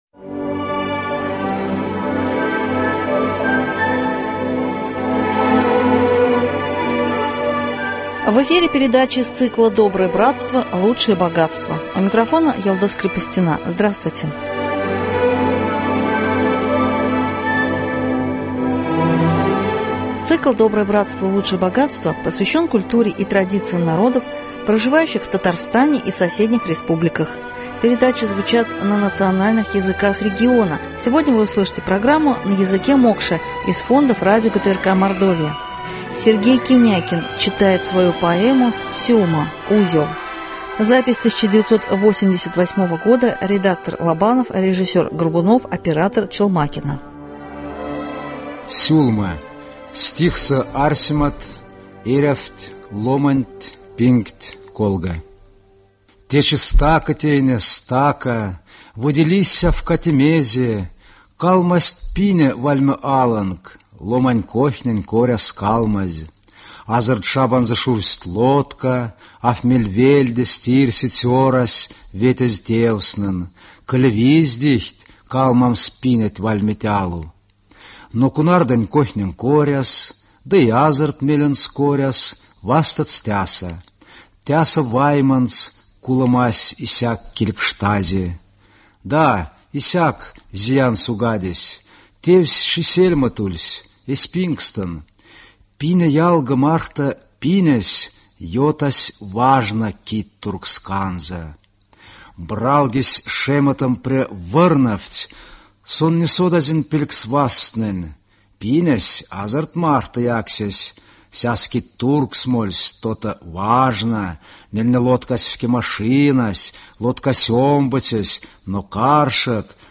Сегодня вы услышите передачу на языке мокша из фондов радио ГТРК Мордовия.